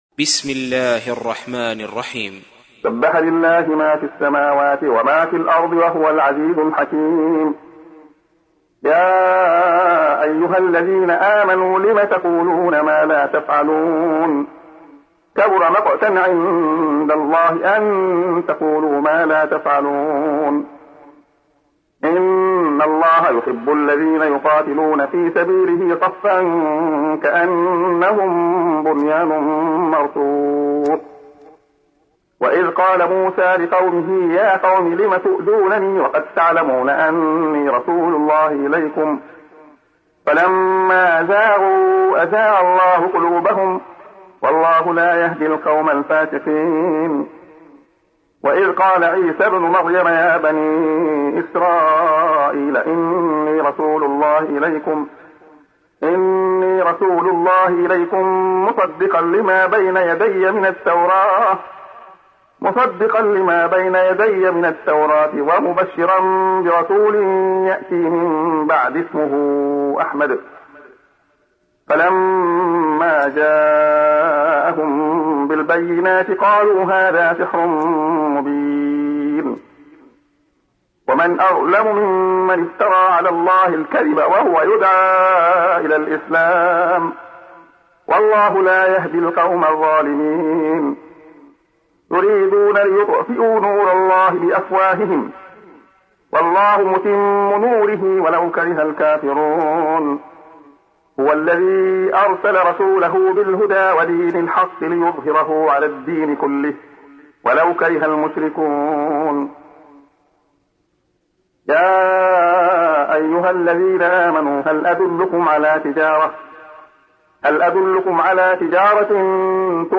تحميل : 61. سورة الصف / القارئ عبد الله خياط / القرآن الكريم / موقع يا حسين